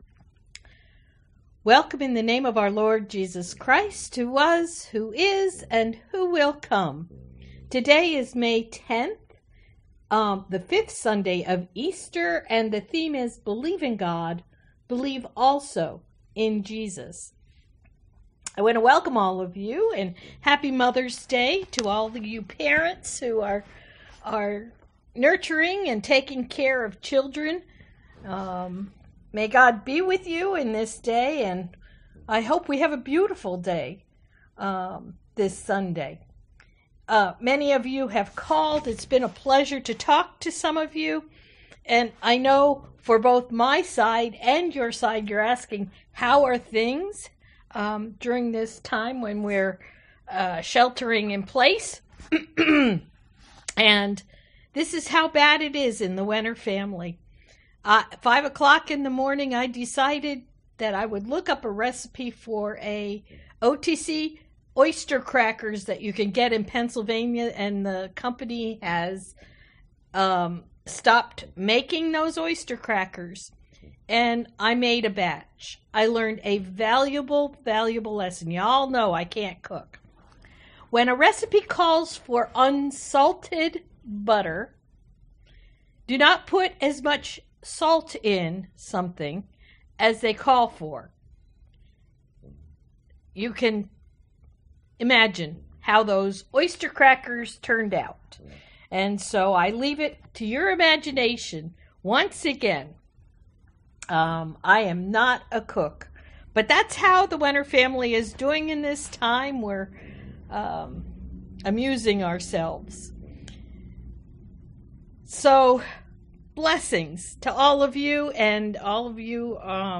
Sunday Worship
Prelude: Vaughan Williams: Organ Prelude on Rhosymedre